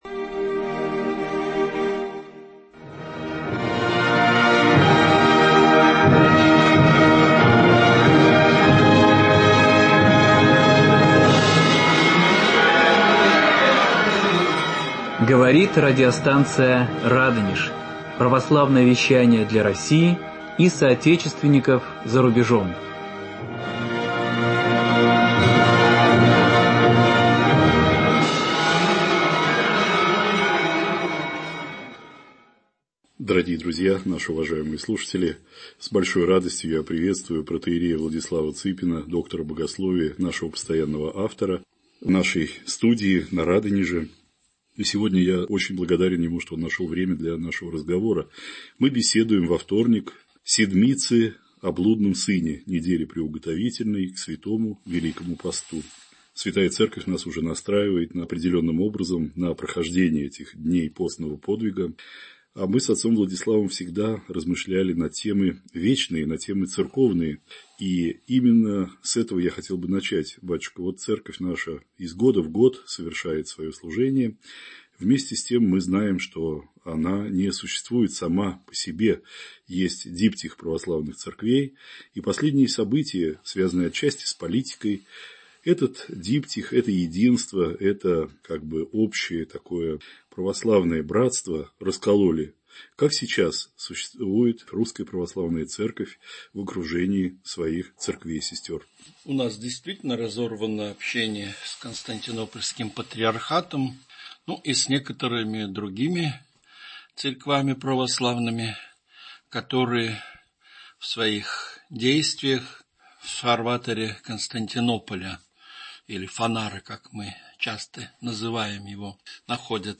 О сегодняшнем состоянии в мировом Православии, о тенденциях к восстановлению Диптиха: беседа